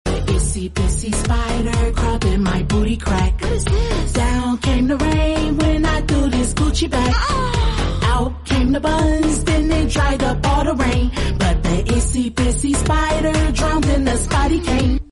I think the song is so catchy and funny bruh LMAOOOO